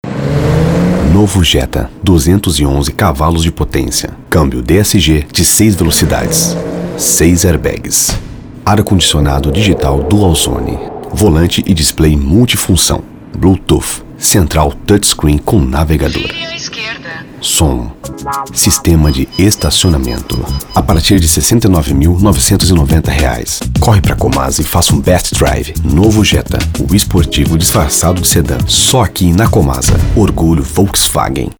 Masculino
Via Casa - SPOT (Voz Caricata) - 30s